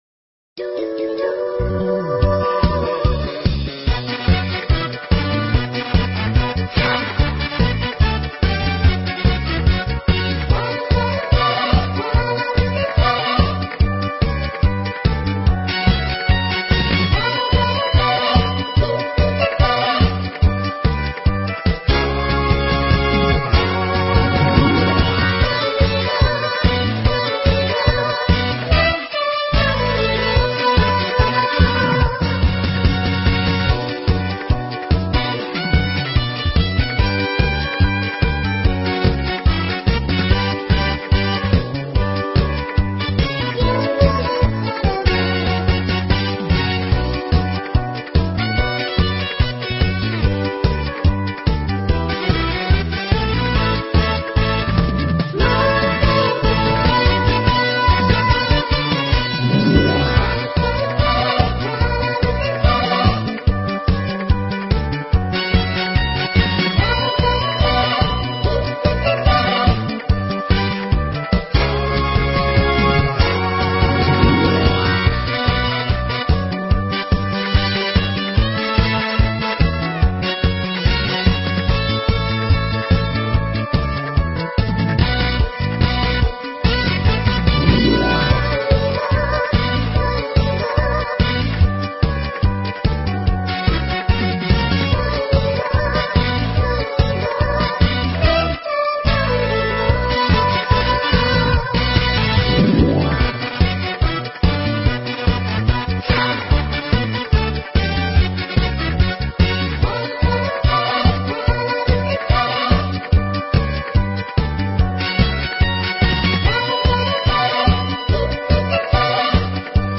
【★반주.명상.가사★】/♬~반주.연주.경음악